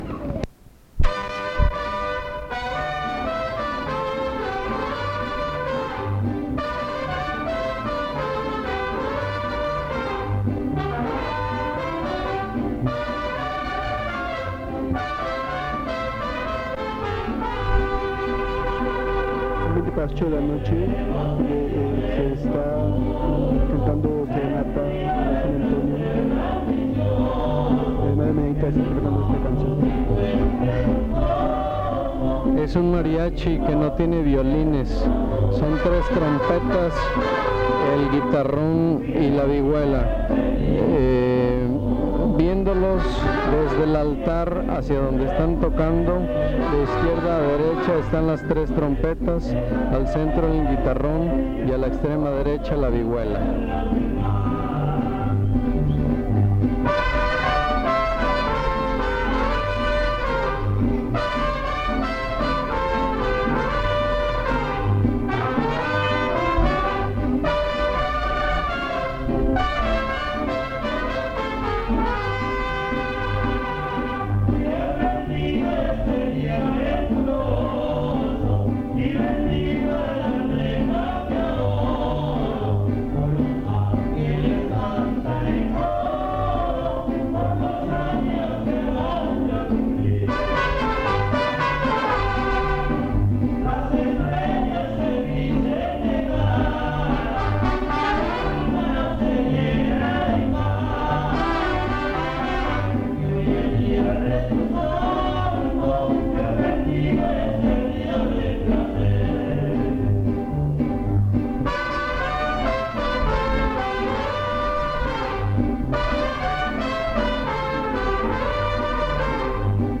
Fiesta de Santiago Tuxtla : investigación previa